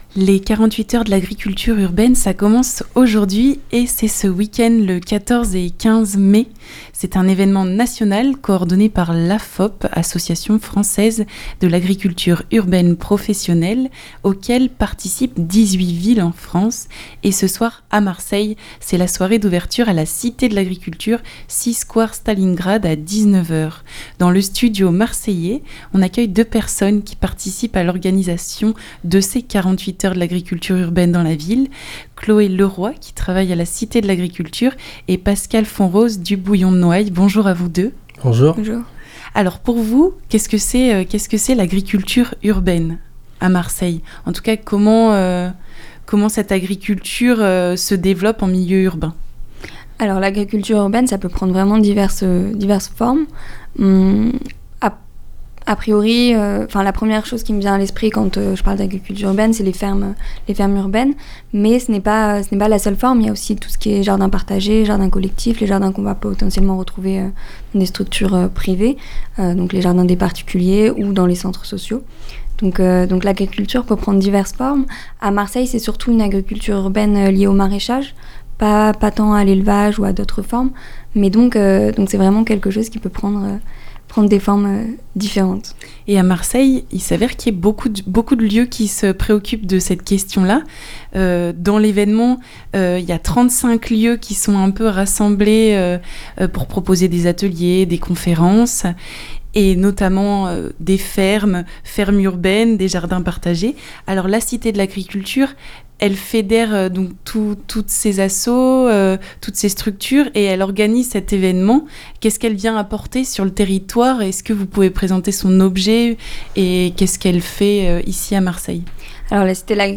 ITW 48H de l'agricultur...